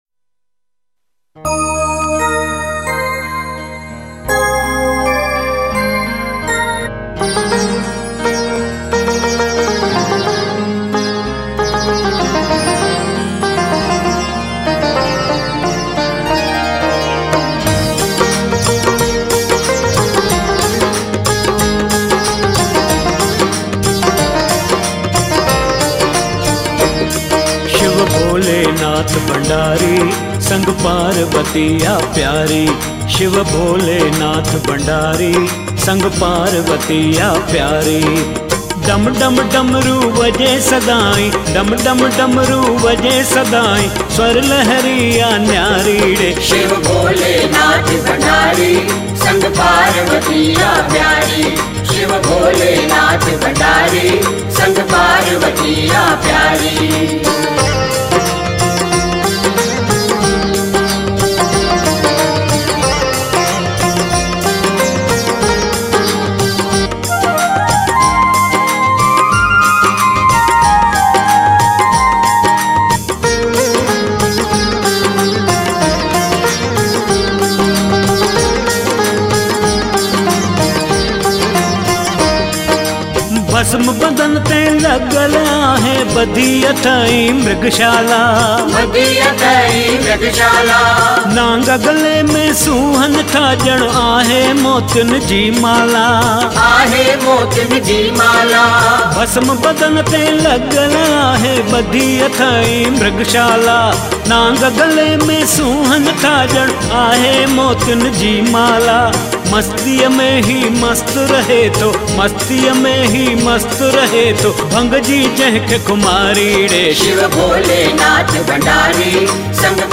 Collection of Sindhi Bhajans